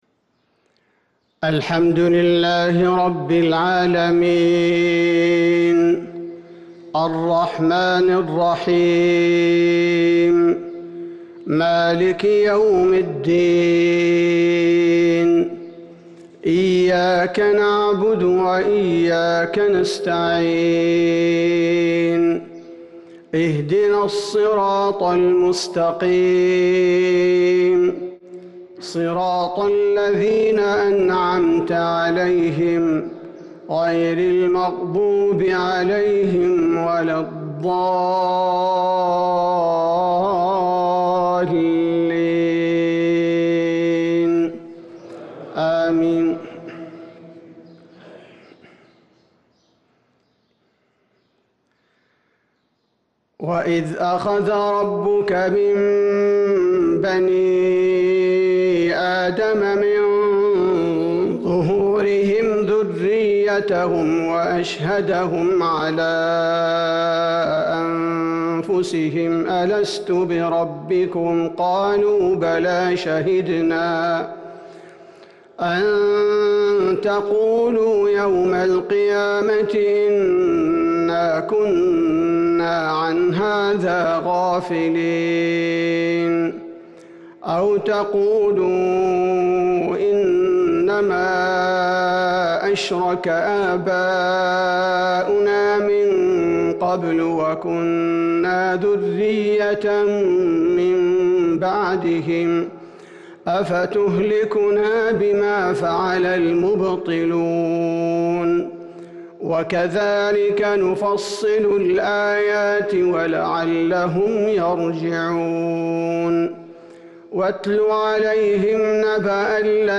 فجر الأربعاء 8-7-1443هـ من سورة الأعراف | Fajr prayer from Surat Al-A'raaf 9-2-2022 > 1443 🕌 > الفروض - تلاوات الحرمين